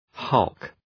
Προφορά
{hʌlk}